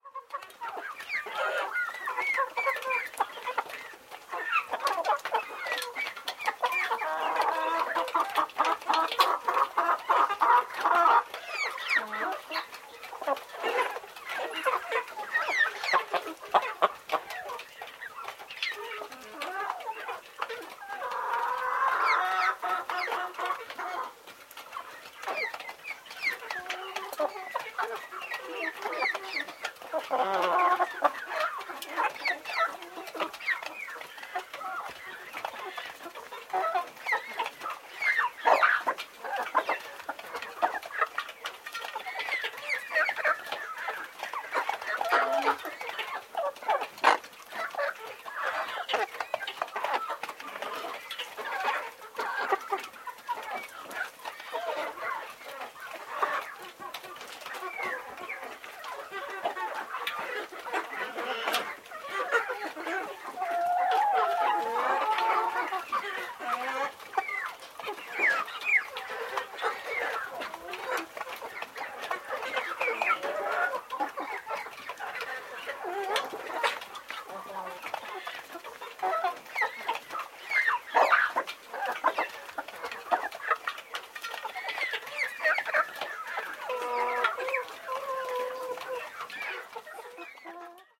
Птицы на сельской ферме звуки